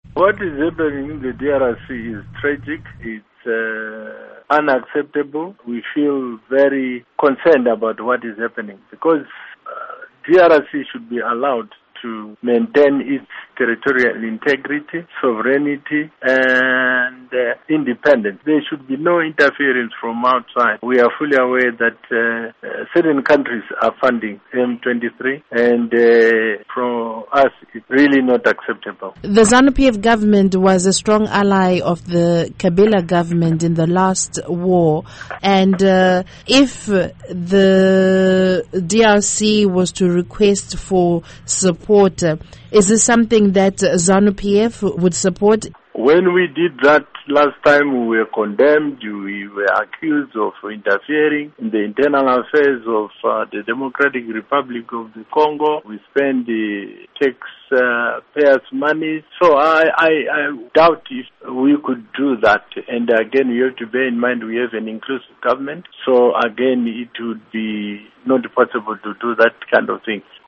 Embed share Interview with Rugare Gumbo by VOA Embed share The code has been copied to your clipboard.